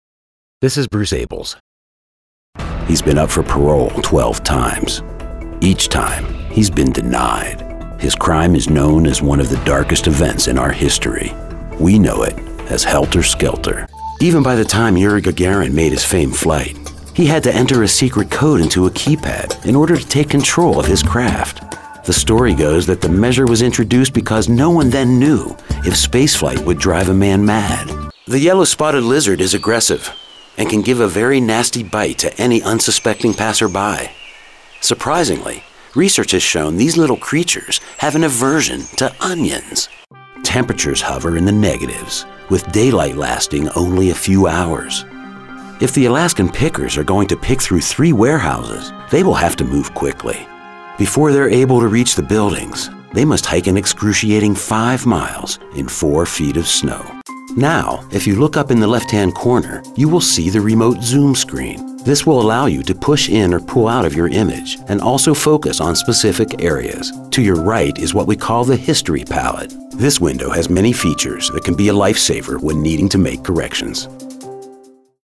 A versatile VO pro with a deep, warm voice and distinctive overtones.
englisch (us)
My voice is like smooth gravel; deep, textured, and unforgettable. Laid-back to high-energy.
I work from a broadcast-quality home studio equipped with Source-Connect.